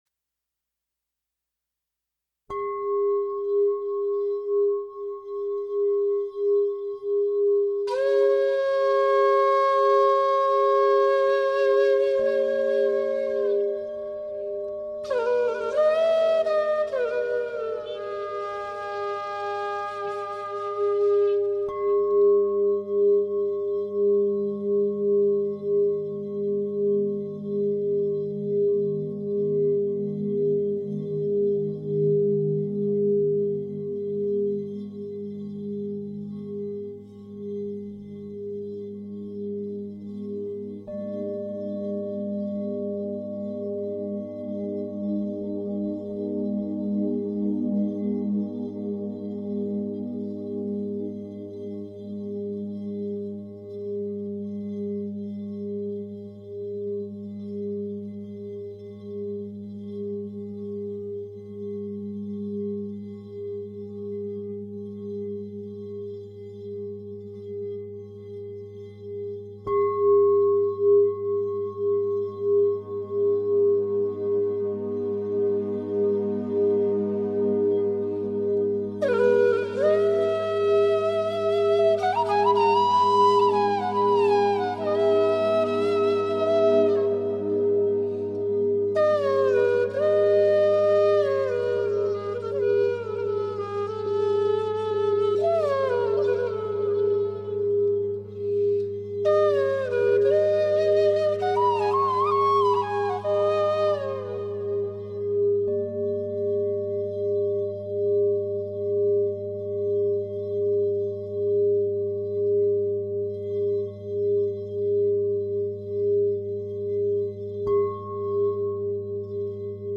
música relajante